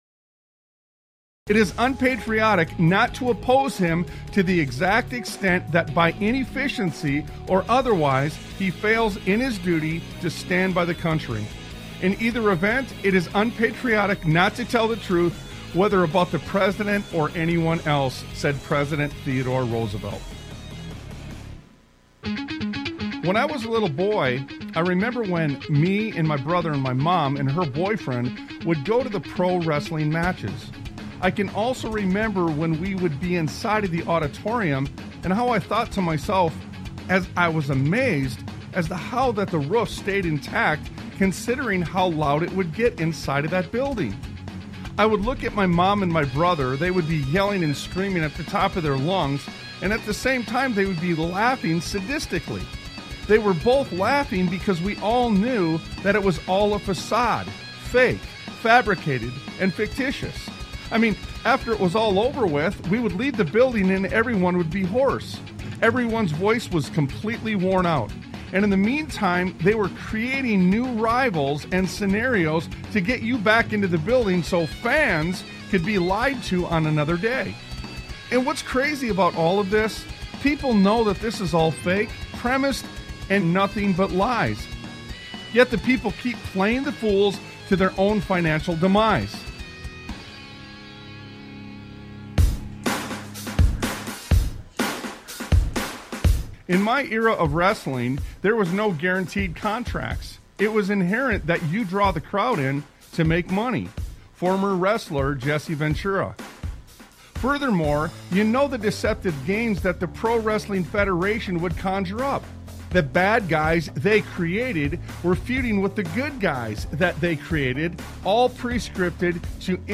Talk Show Episode, Audio Podcast, Sons of Liberty Radio and Divided We Fall on , show guests , about Divided We Fall, categorized as Education,History,Military,News,Politics & Government,Religion,Christianity,Society and Culture,Theory & Conspiracy